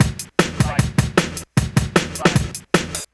drums05.wav